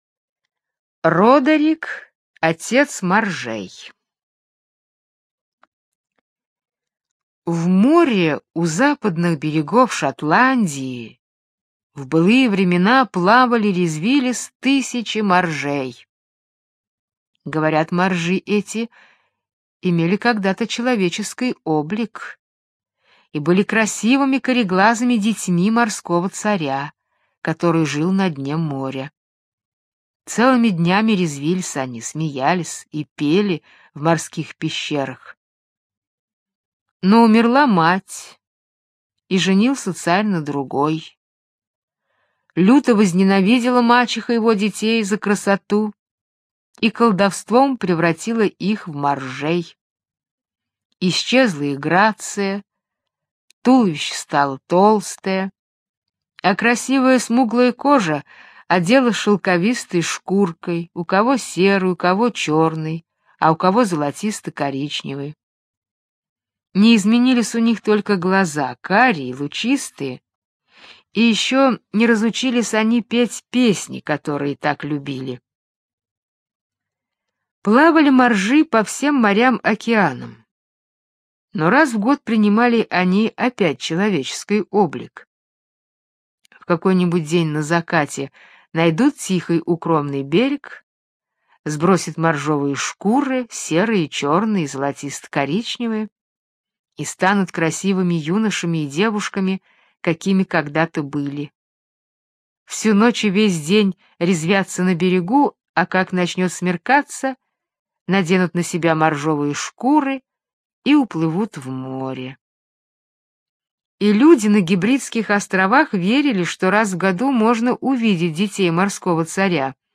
Родерик-отец моржей - шотландская аудиосказка - слушать онлайн